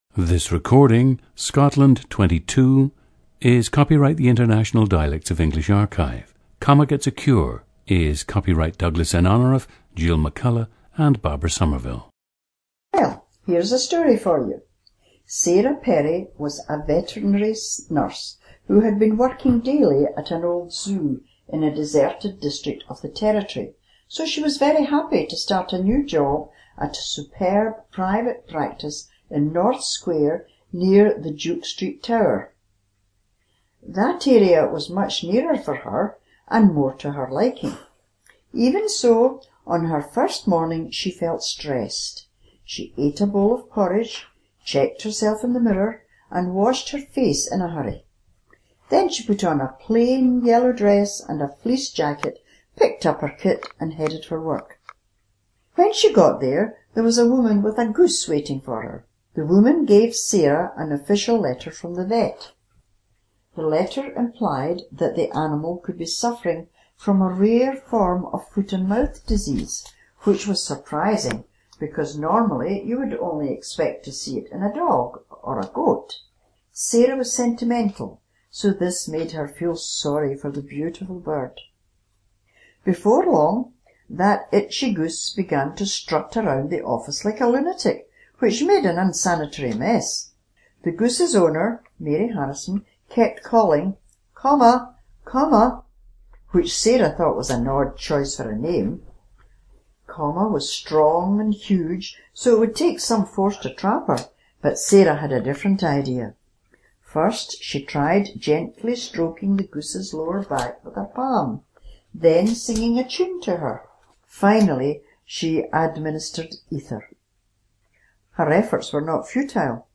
GENDER: female
Tiree has a distinctive dialect, as do all the islands of the inner and outer Hebrides.
The subject’s accent is more Glasgow-inflected Tiree than the other way around.
Oral posture is high jaw, with very little obvious facial movement and minimal but extremely efficient lip-pursing movement. /s/ and /ʃ/ are very close; I believe the tongue tip for /s/ is curved down.
• Recordings of accent/dialect speakers from the region you select.